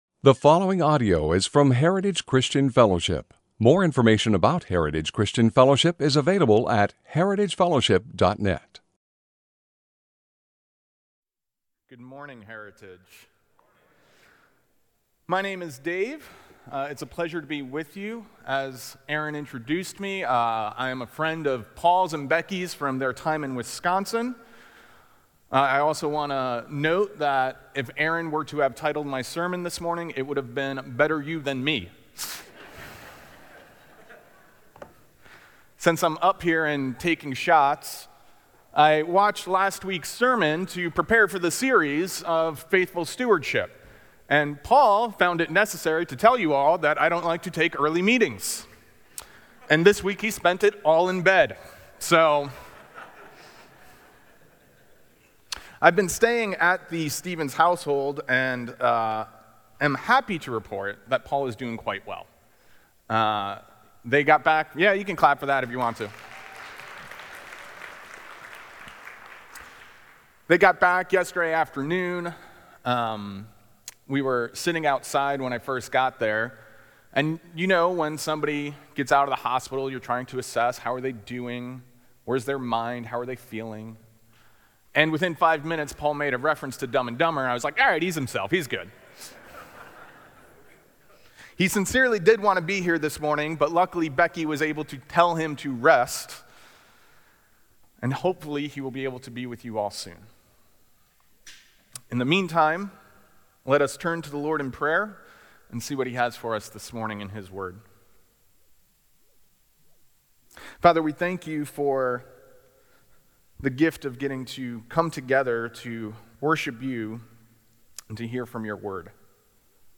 Sermons - Heritage Christian Fellowship | Of Medford, OR
From Series: "Guest Speaker"